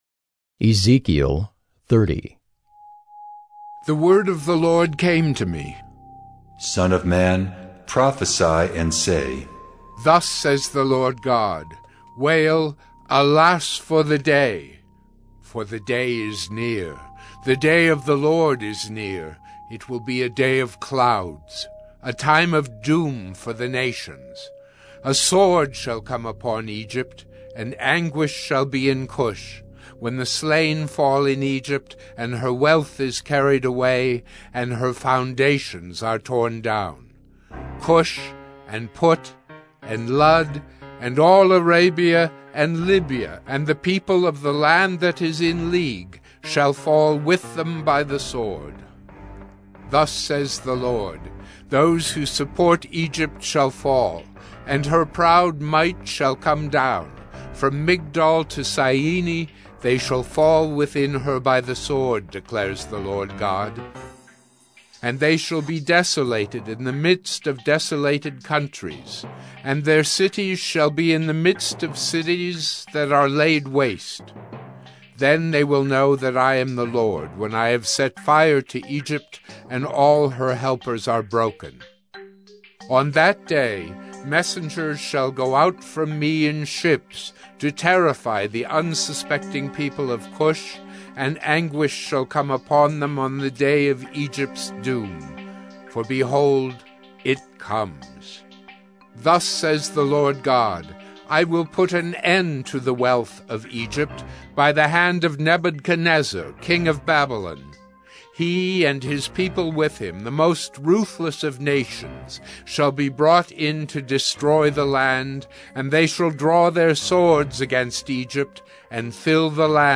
“Listening to God” Bible Reading & Devotion: Sept. 29, 2021 – Ezekiel 030